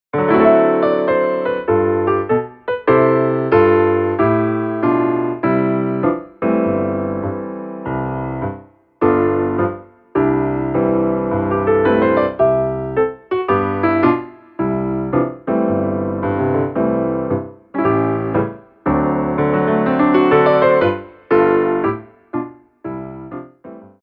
CHANGE OF TEMPO